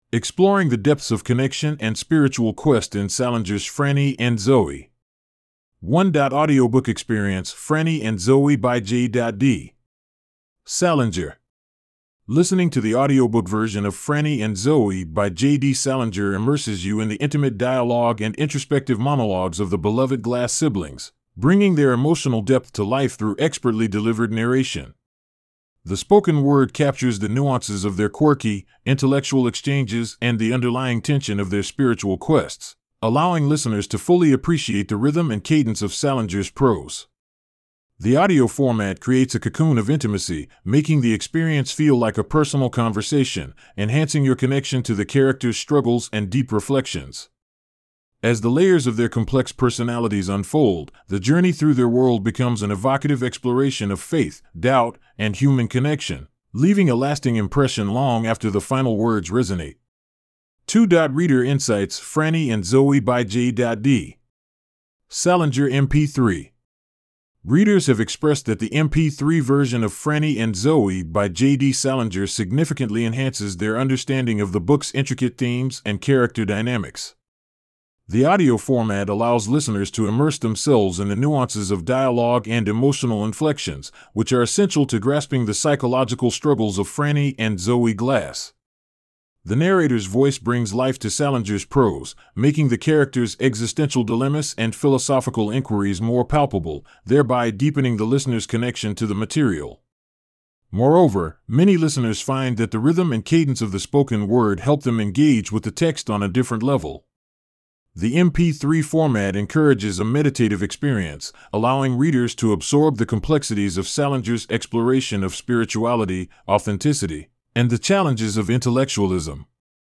1.Audiobook Experience:Franny and Zooey byJ.D. Salinger